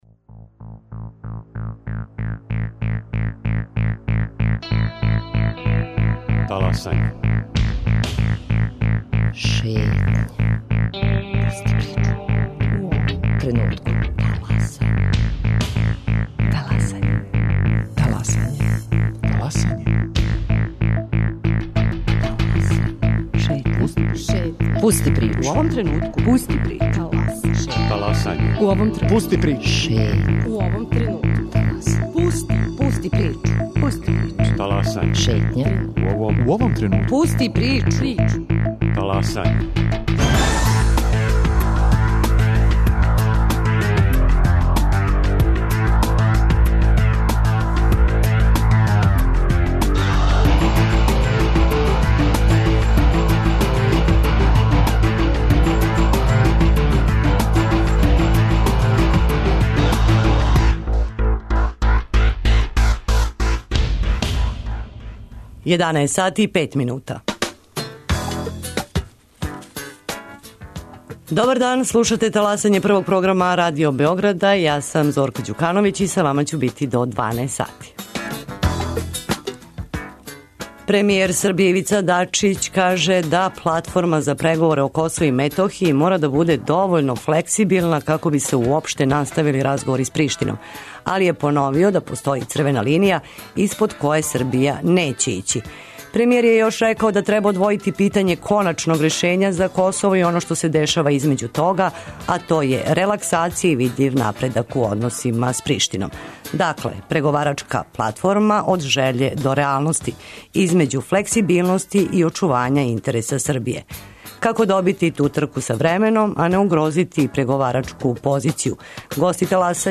Гости Таласања су Милован Дрецун, председник скупштинског одбора за Косово и Метохију и Оливер Ивановић, бивши државни секретар ресорног министарства.